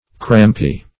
Search Result for " crampy" : The Collaborative International Dictionary of English v.0.48: Crampy \Cramp"y\ (kr[a^]mp"[y^]), 1. Affected with cramp.
crampy.mp3